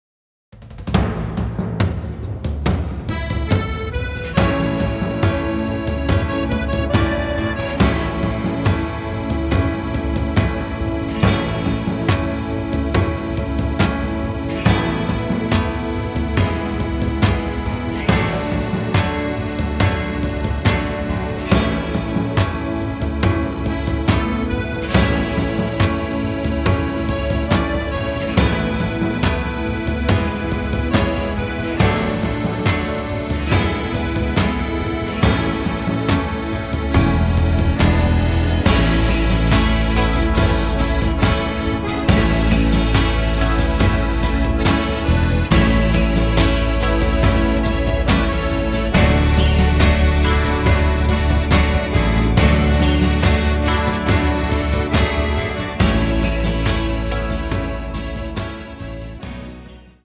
Gute Laune Titel 1A